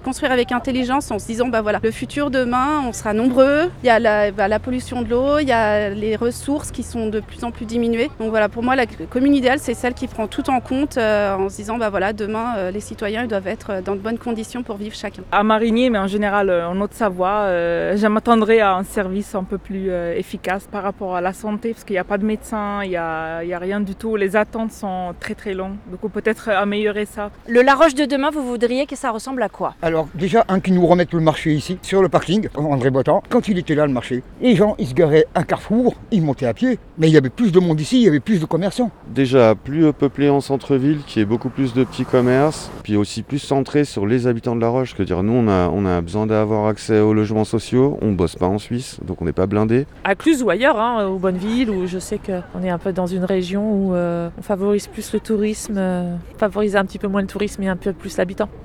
La Rédaction est allée vous poser la question dans la Vallée de l'Arve.